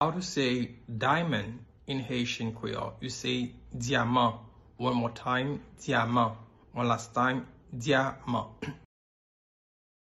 Pronunciation:
Listen to and watch “Dyaman” audio pronunciation in Haitian Creole by a native Haitian  in the video below:
8.How-to-say-Diamond-in-Haitian-Creole-–-Dyaman-pronunciation-.mp3